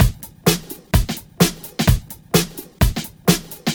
VMH1 Minimal Beats 04.wav